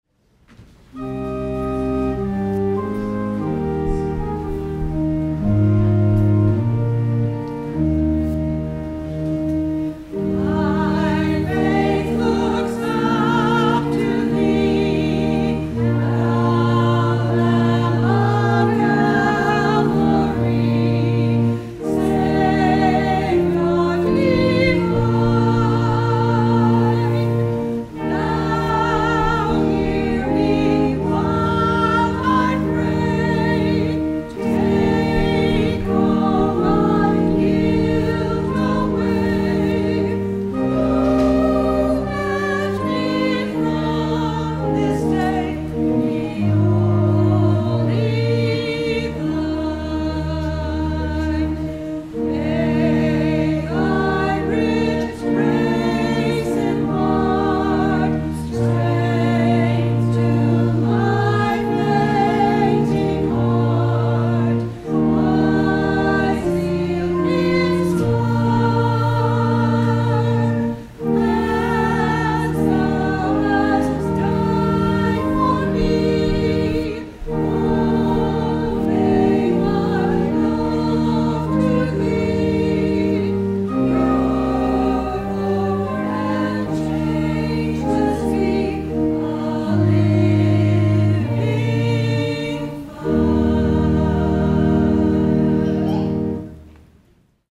Anthem